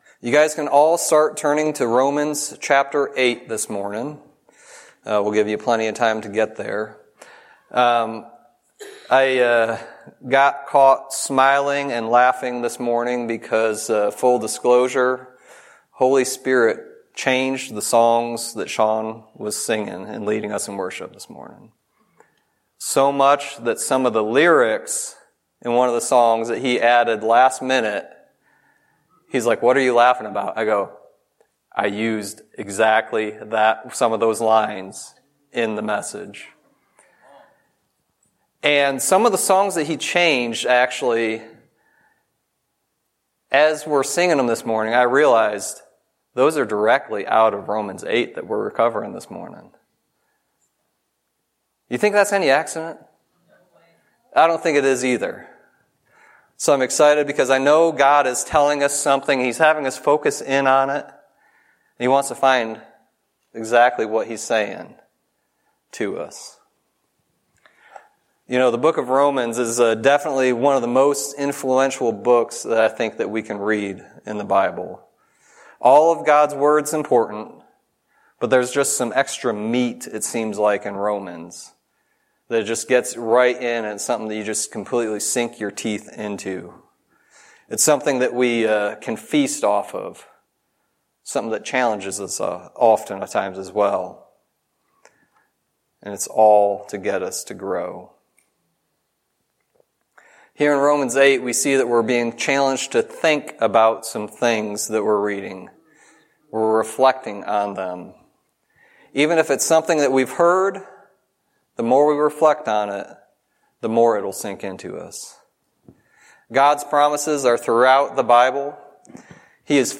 Sermon messages available online.
Romans 8:31-39 Service Type: Sunday Teaching Jesus came and showed God’s love to us giving himself up on the cross.